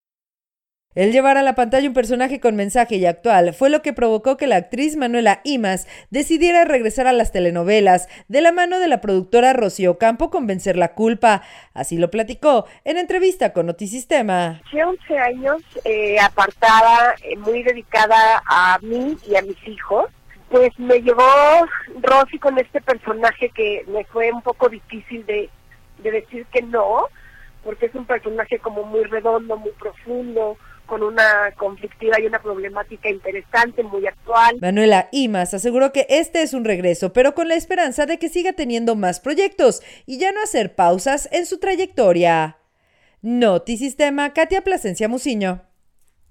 El llevar a la pantalla un personaje con mensaje y actual, fue lo que provocó que la actriz Manuela Ímaz decidiera regresar a las telenovelas, de la mano de la productora Rosy Ocampo con “Vencer la culpa”, así lo platicó en entrevista con Notisistema.